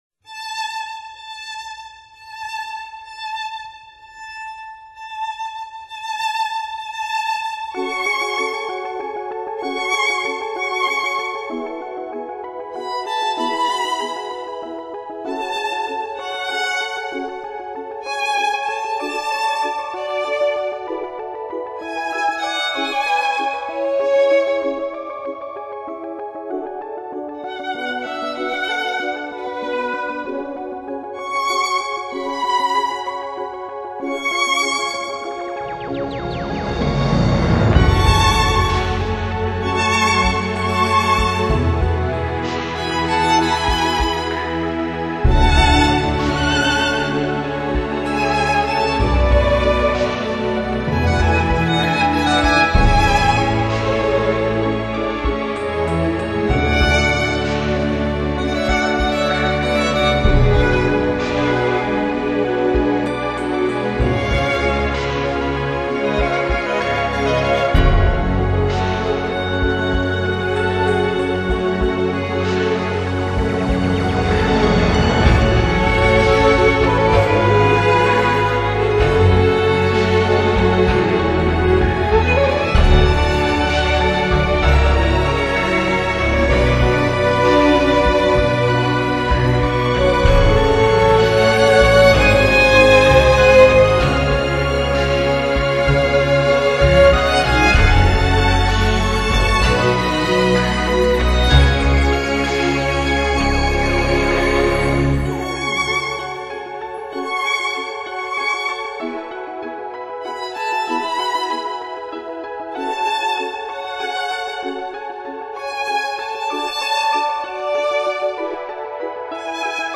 双小提琴的全新组合、学院男孩的超魅力。